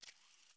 SD_SFX_GrapplingHook_Release.wav